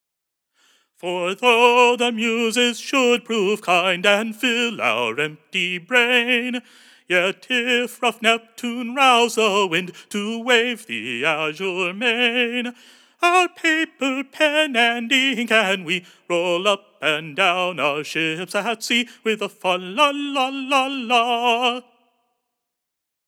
29_sackville_nobleseamans_st.2_shackleyhay_major.mp3 (911.28 KB)